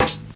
Medicated Snare 21.wav